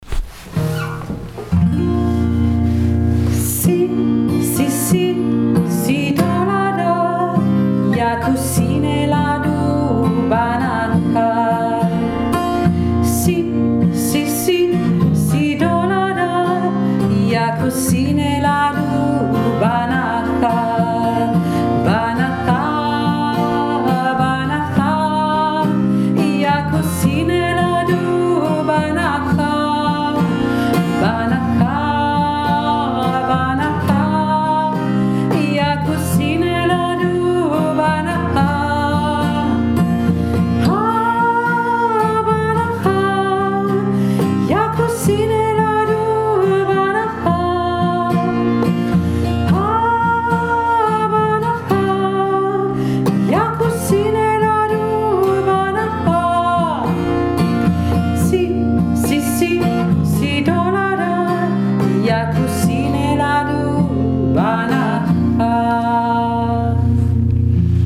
afrikanisches Lied